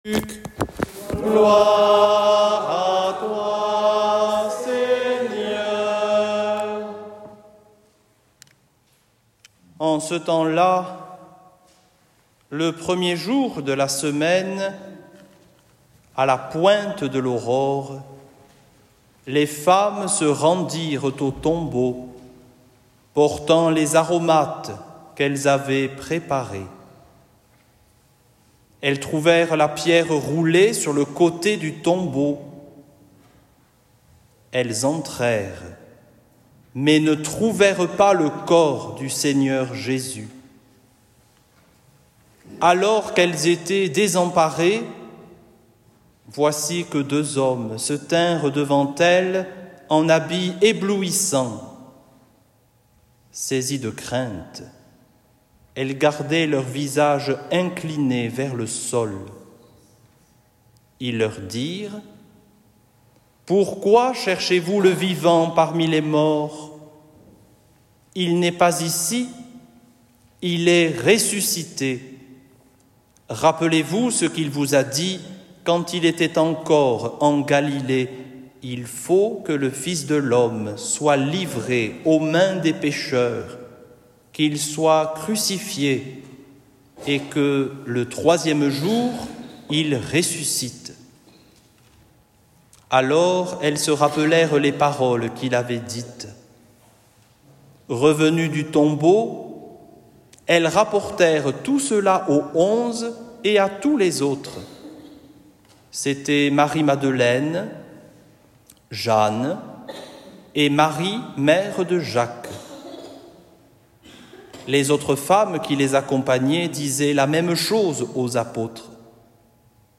evangile-vigile-de-paques-2025.mp3